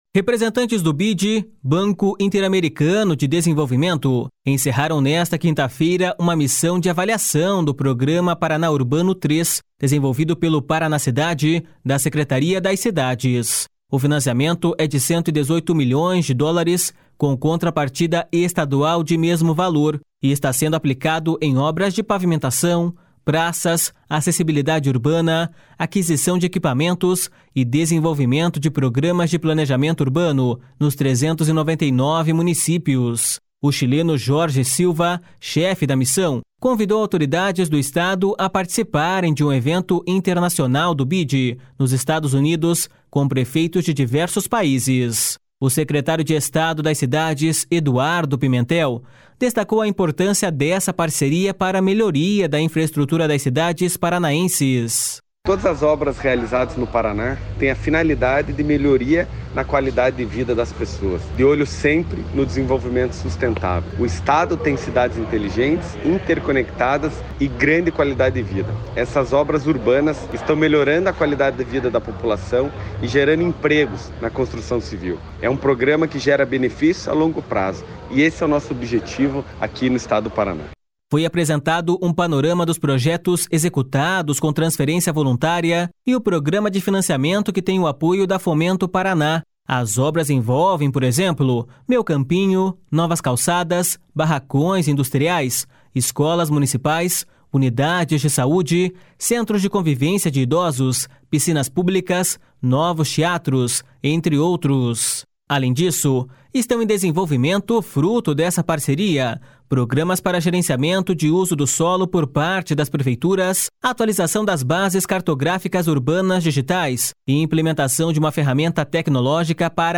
O secretário de Estado das Cidades, Eduardo Pimentel, destacou a importância dessa parceria para melhoria da infraestrutura das cidades paranaenses.// SONORA EDUARDO PIMENTEL.//
Repórter